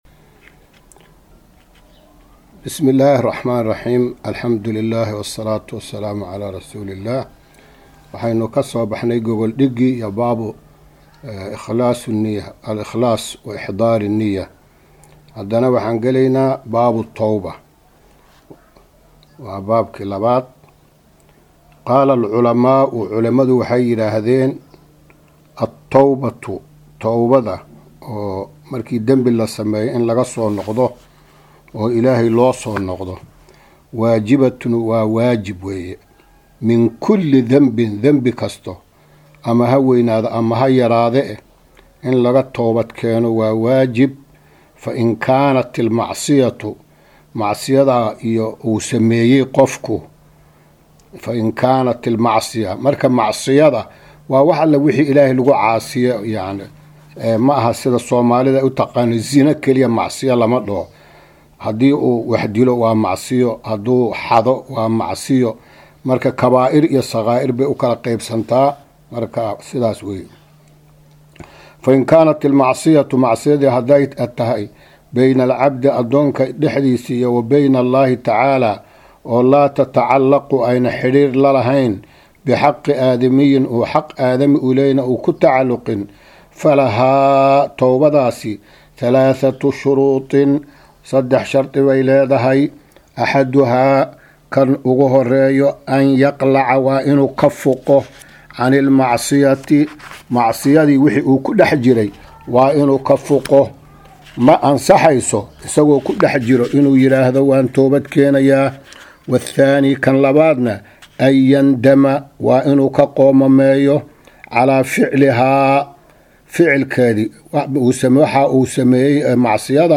Maqal- Riyaadu Saalixiin – Casharka 3aad